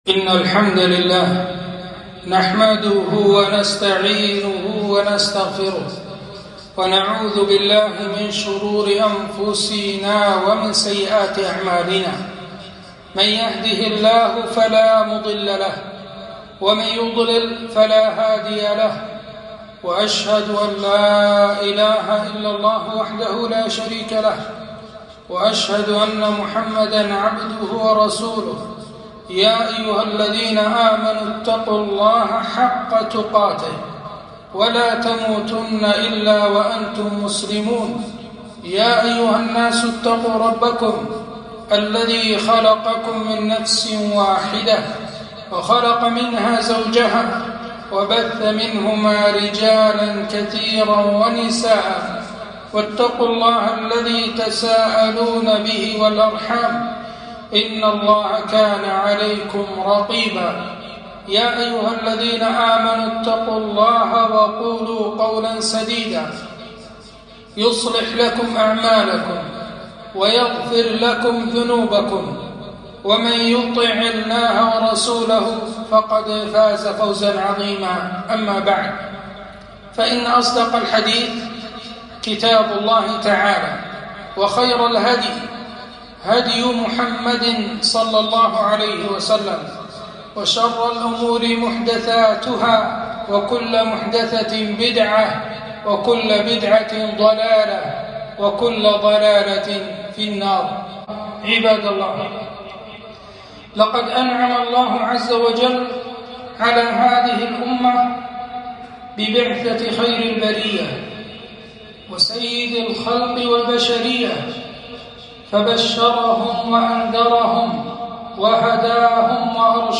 خطبة - مكانة السنة النبوية